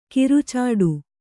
♪ kirucāḍu